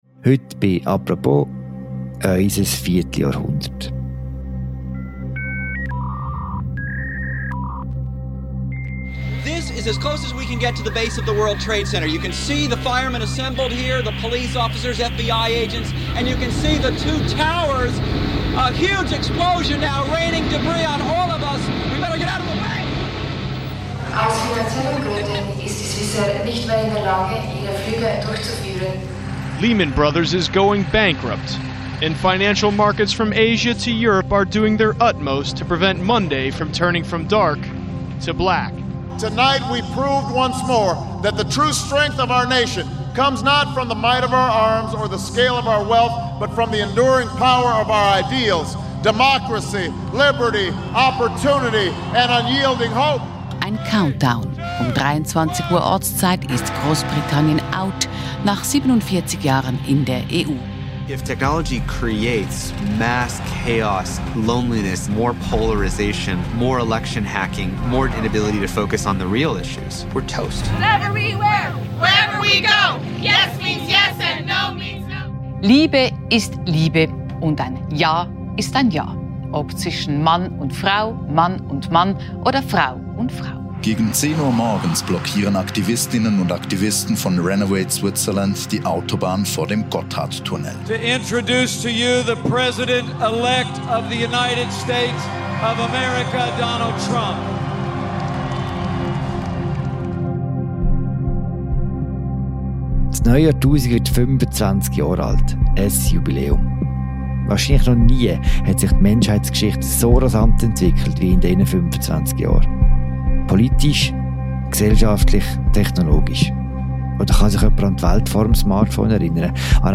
Im Podcast blickt die Redaktion zurück und zeigt die grossen Linien auf: von Geopolitik, über Gesellschaft bis Popkultur.